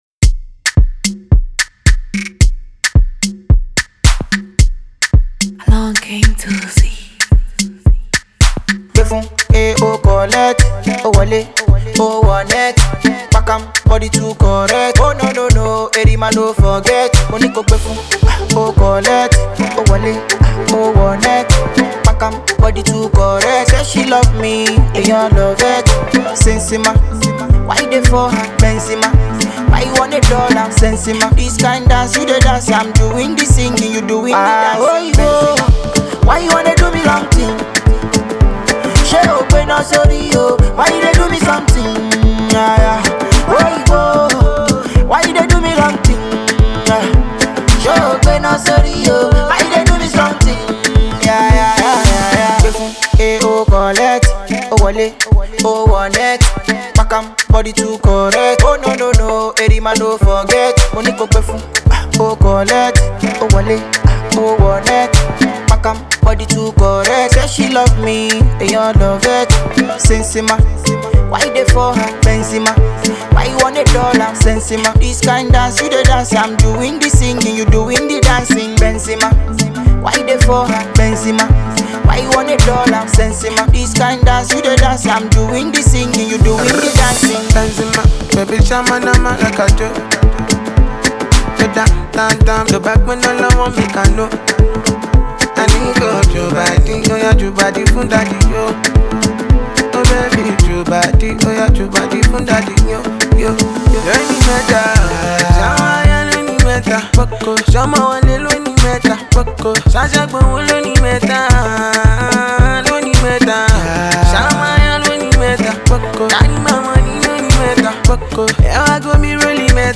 infectious banger
energetic vocals
rhythmic Afro beats